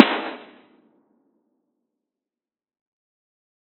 TM-88 Clap #05.wav